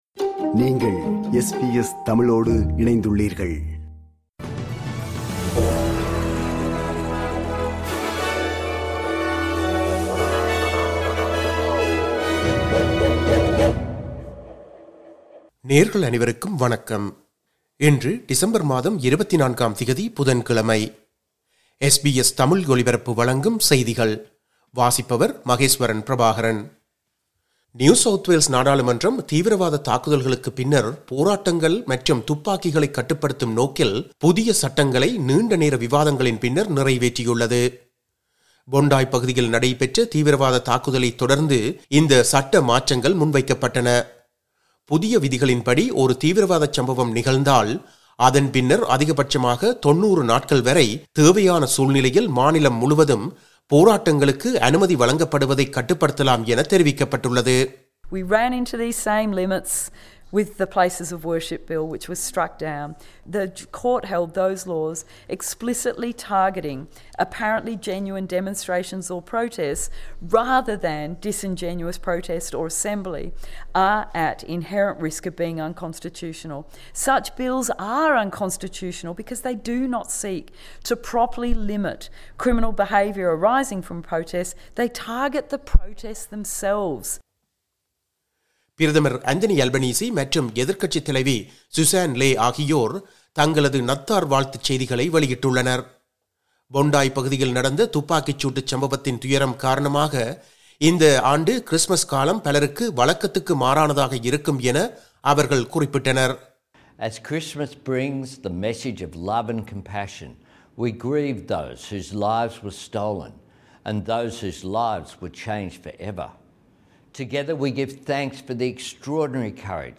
SBS தமிழ் ஒலிபரப்பின் இன்றைய (புதன்கிழமை 24/12/2025) செய்திகள்.